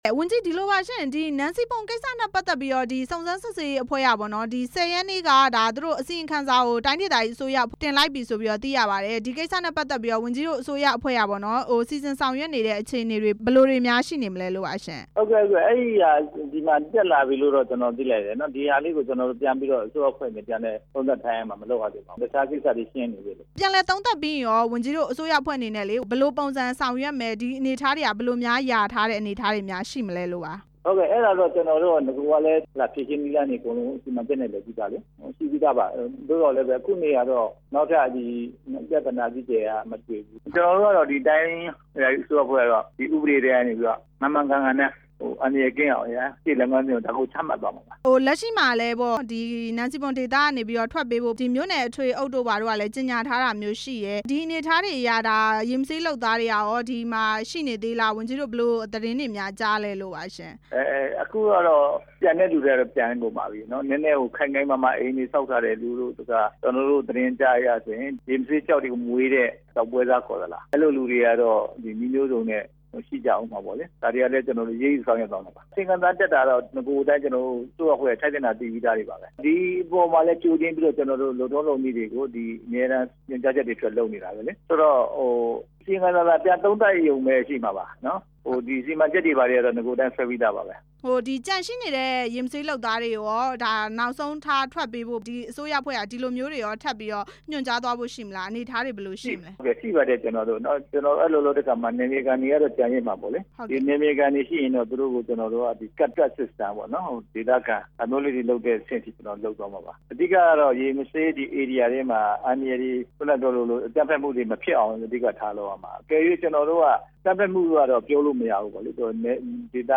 နန်စီပွန်ဒေသ နောက်ဆက်တွဲအခြေအနေ မေးမြန်းချက်